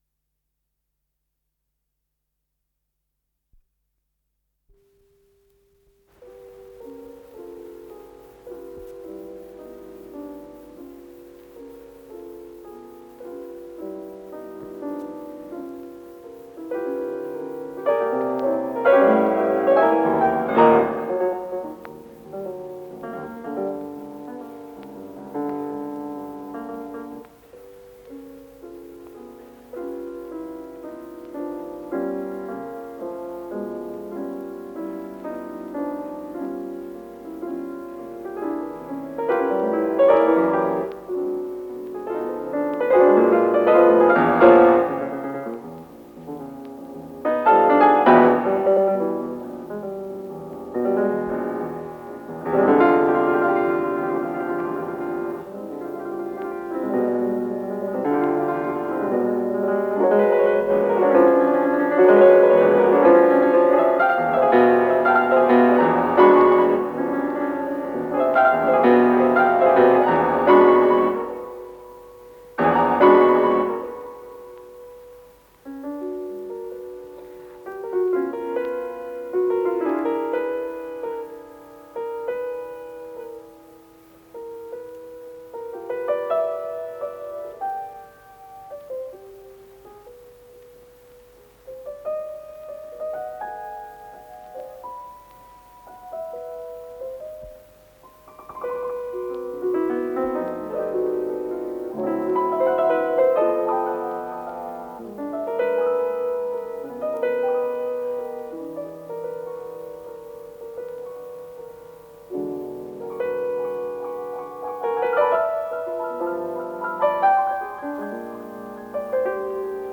с профессиональной магнитной ленты
ПодзаголовокДля фортепиано, запись из Концертного зала "Карнеги-Холл" от 25.02.1953г., Модерато квази анданте
ИсполнителиВладимир Горовиц - фортепиано